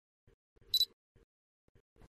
Tiếng Lấy nét, Focus, Click… Camera máy ảnh
Tiếng Lấy nét, Focusing của Camera DSLR, Máy ảnh cơ Tiếng Camera DSLR, Máy ảnh cơ Focus, lấy nét…
Thể loại: Tiếng đồ công nghệ
Description: Tiếng lấy nét (focus sound), tiếng focus beep báo hiệu đã khóa nét, tiếng click đặc trưng khi nhấn nút chụp, tiếng "bíp", tiếng "tít" báo hiệu dùng để tái hiện trải nghiệm sử dụng máy ảnh, tạo cảm giác chân thực, gần gũi và tăng tính điện ảnh cho cảnh quay...
tieng-lay-net-focus-click-camera-may-anh-www_tiengdong_com.mp3